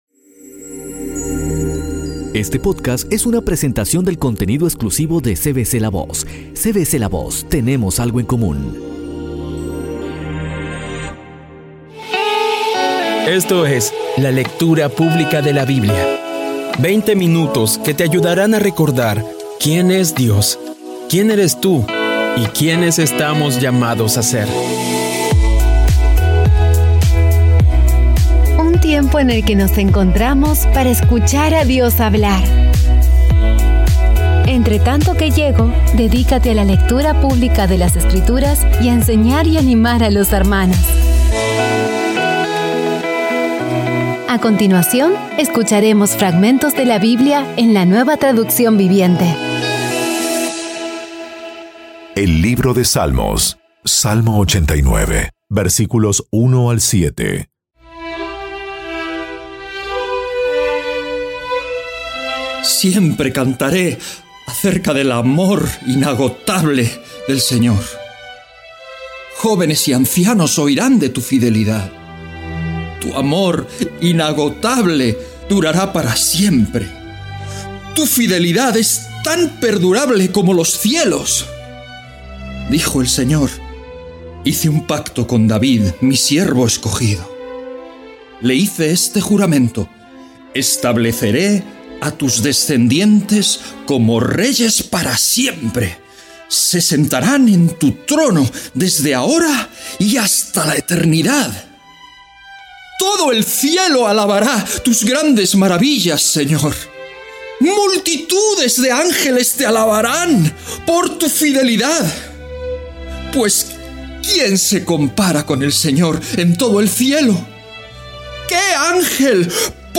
Audio Biblia Dramatizada por CVCLAVOZ / Audio Biblia Dramatizada Episodio 214